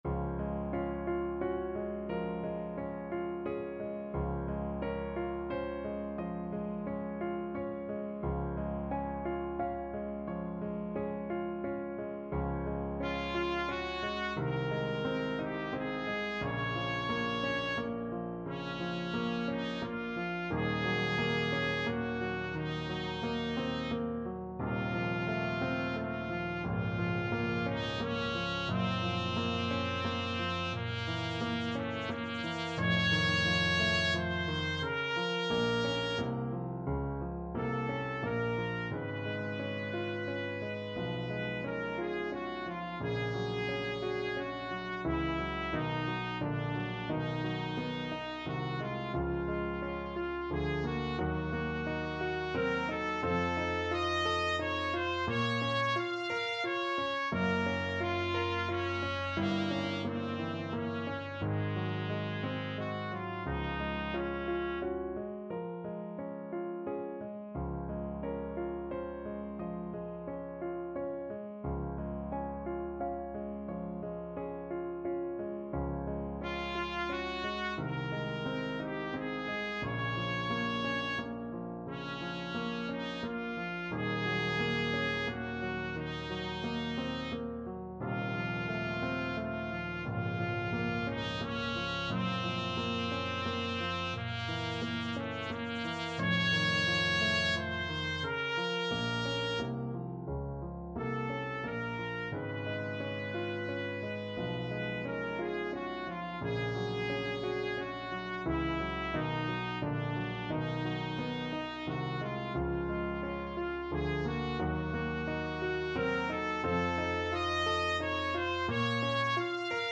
Classical Fanny Mendelssohn Die Mainacht from 6 Lieder, Op.9 Trumpet version
Trumpet
6/4 (View more 6/4 Music)
~ = 88 Andante
Ab4-F6
Db major (Sounding Pitch) Eb major (Trumpet in Bb) (View more Db major Music for Trumpet )
Classical (View more Classical Trumpet Music)
f_mendelssohn_6_lieder_op9_die_mainacht_TPT.mp3